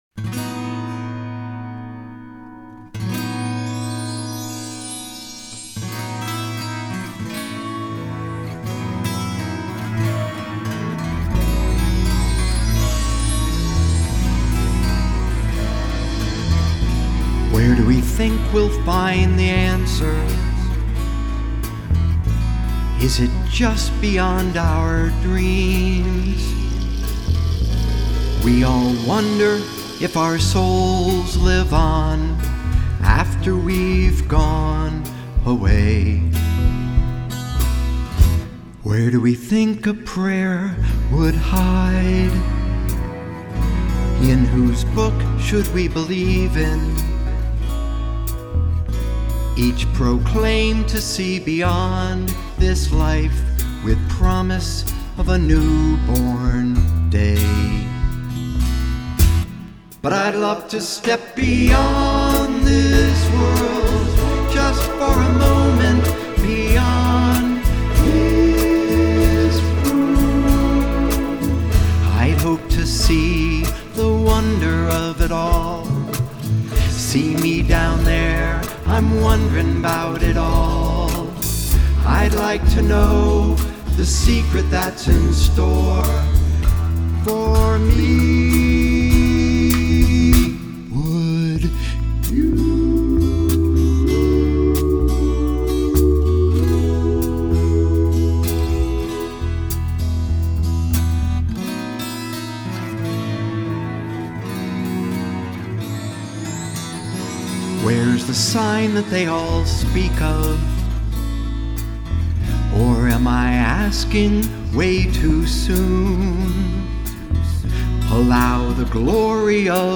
REMASTERED SONGS